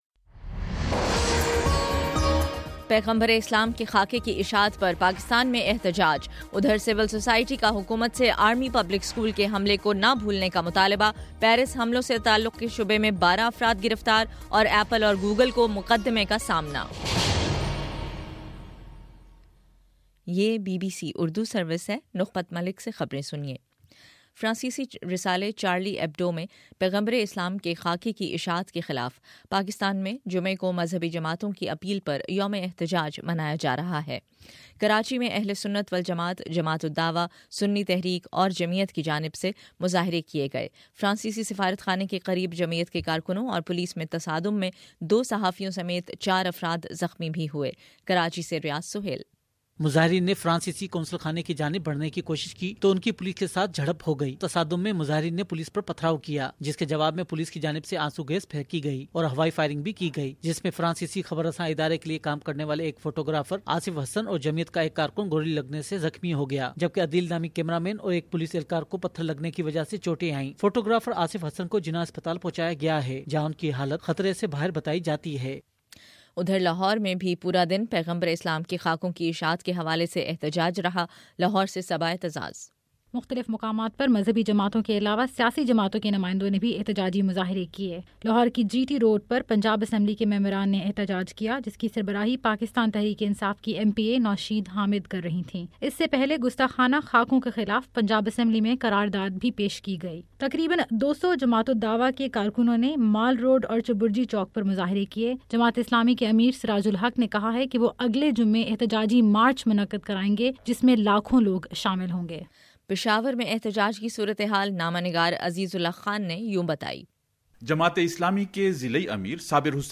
جنوری 16: شام سات بجے کا نیوز بُلیٹن